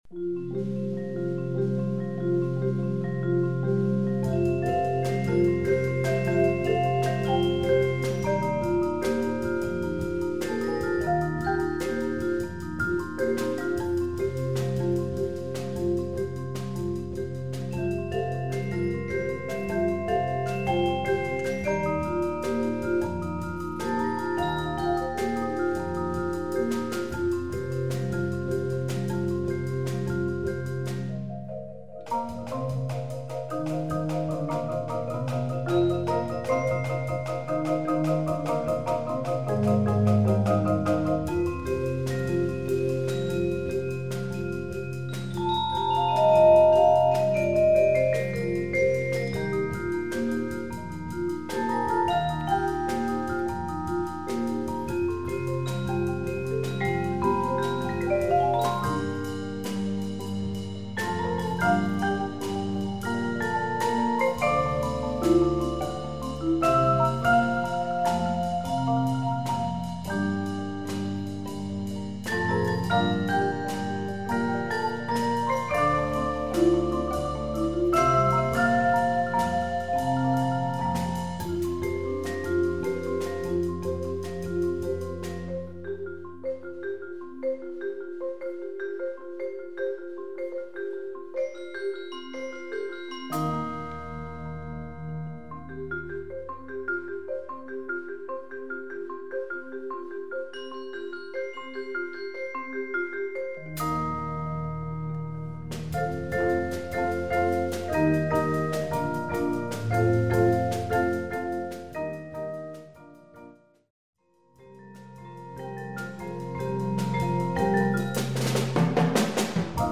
Genre: Percussion Ensemble